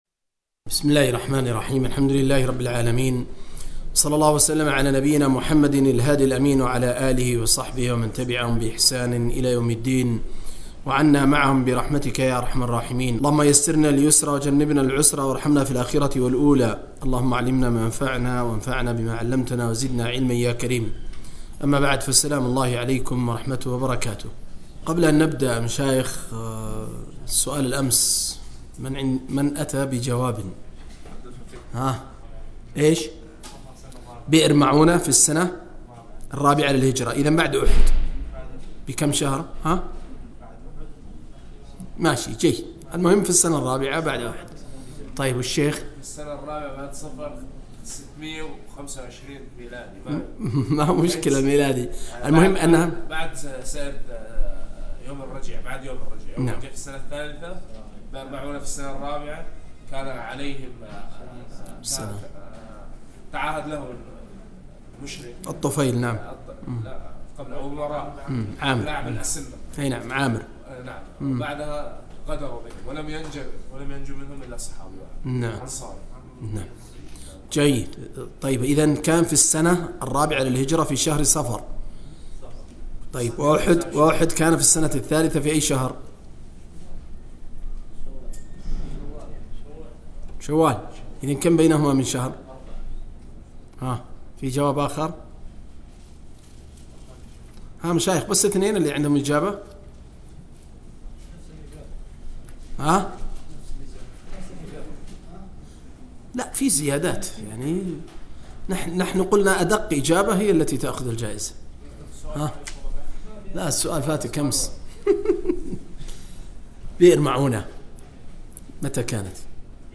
078- عمدة التفسير عن الحافظ ابن كثير رحمه الله للعلامة أحمد شاكر رحمه الله – قراءة وتعليق –